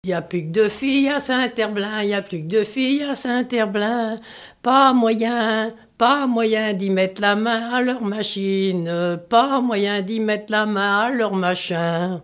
Mémoires et Patrimoines vivants - RaddO est une base de données d'archives iconographiques et sonores.
chant de conscrit
Pièce musicale inédite